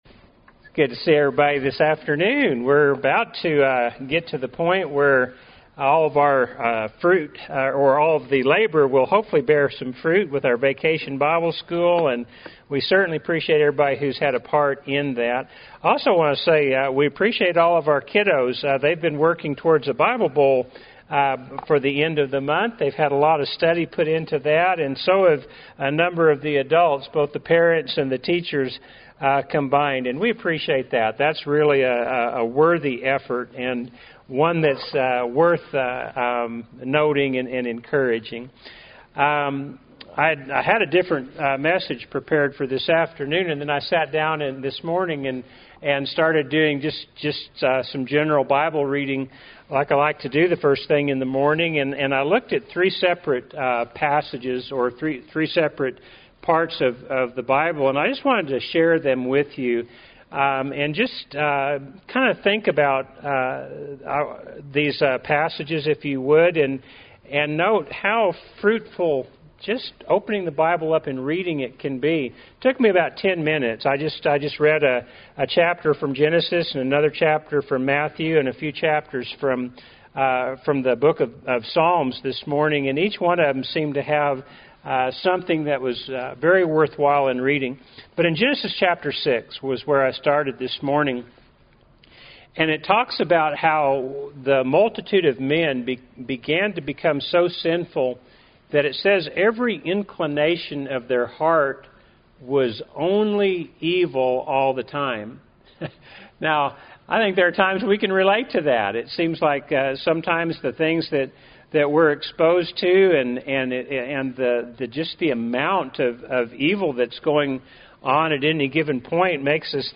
Happy Church of Christ Listen to Sermons
Weekly sermons